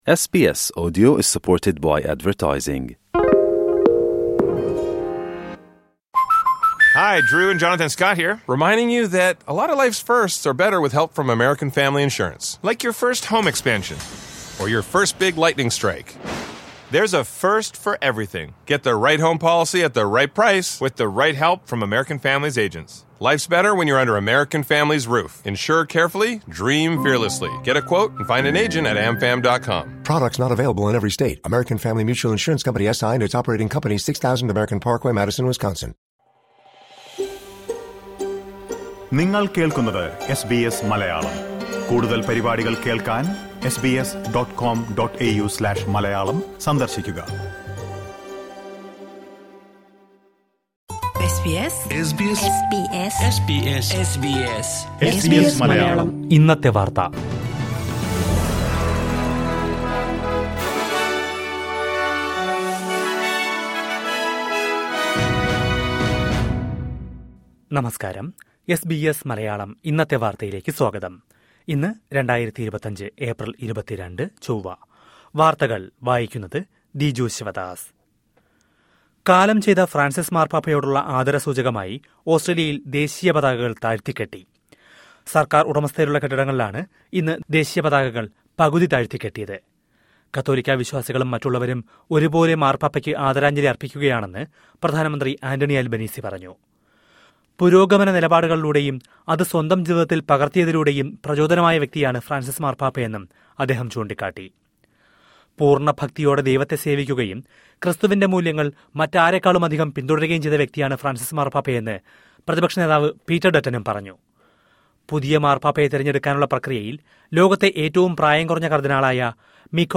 2025 ഏപ്രില്‍ 22ലെ ഓസ്‌ട്രേലിയയിലെ ഏറ്റവും പ്രധാന വാര്‍ത്തകള്‍ കേള്‍ക്കാം...